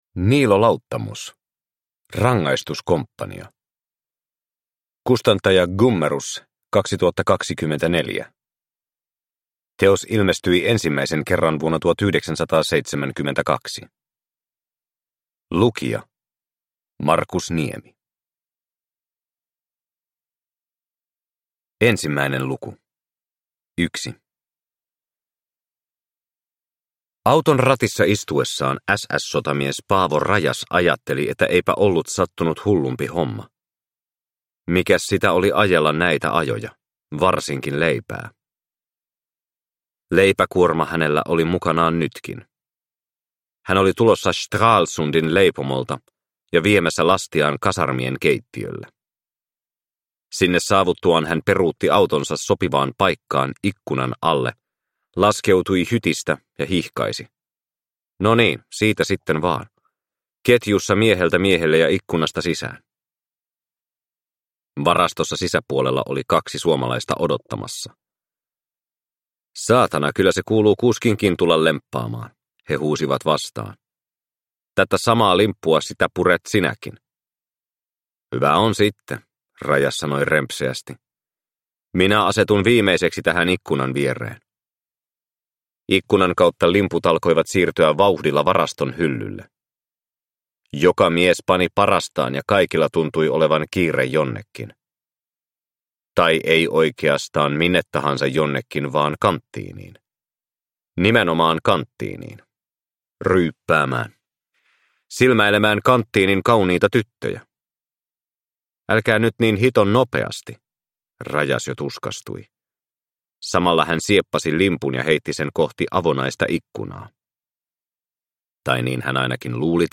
Rangaistuskomppania (ljudbok) av Niilo Lauttamus